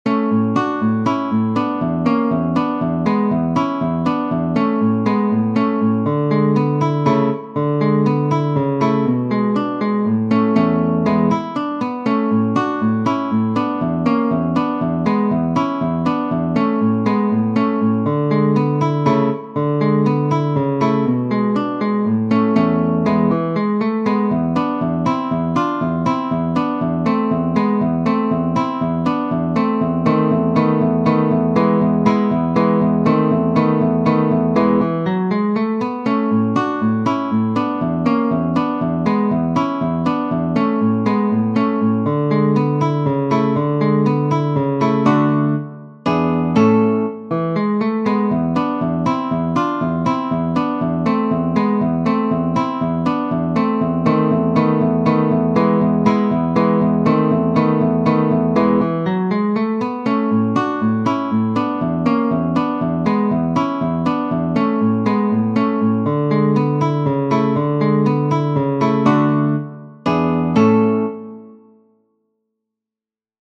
op 39 – n° 21 – Andante – in La[►][-♫-]